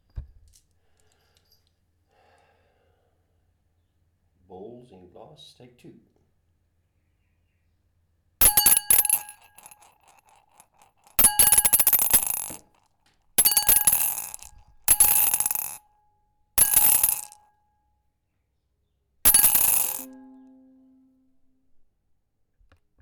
Marbels in Glass 2
Bottle clang Ding Glass Marbles Ring Ting sound effect free sound royalty free Sound Effects